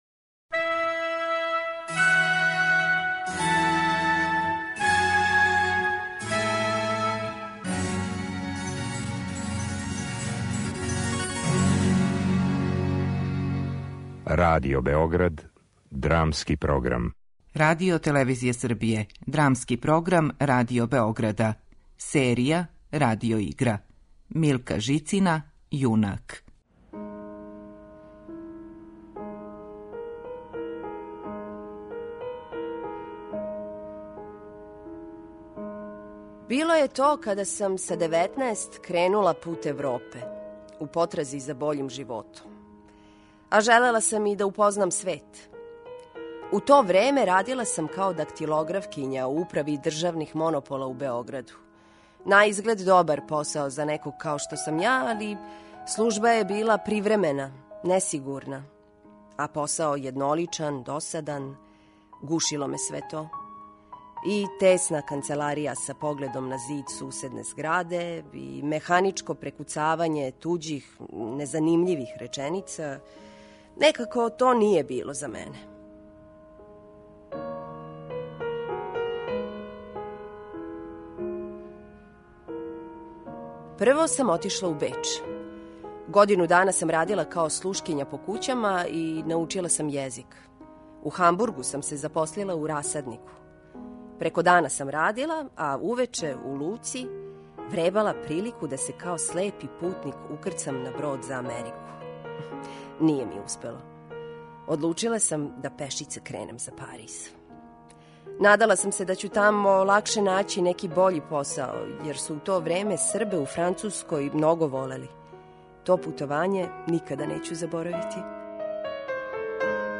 Драмски програм: Радио игра
Радио игра